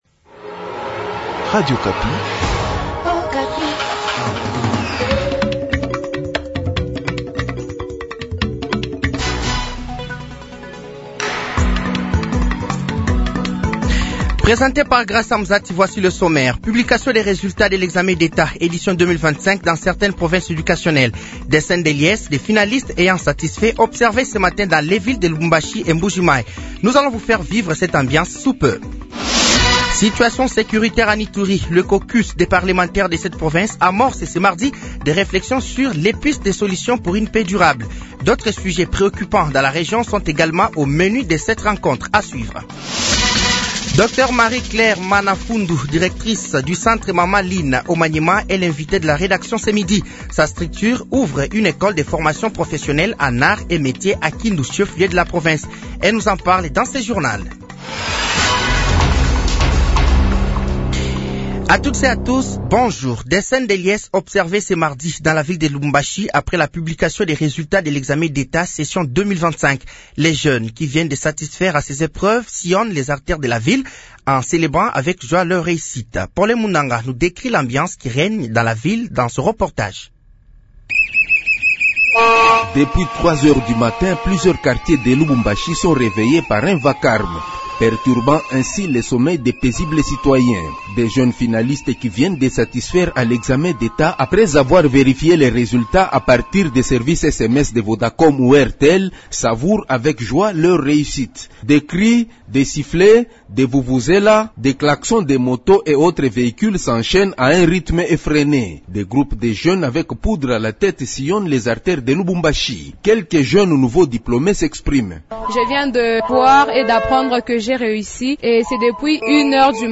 Journal français de 12h de ce mardi 05 août 2025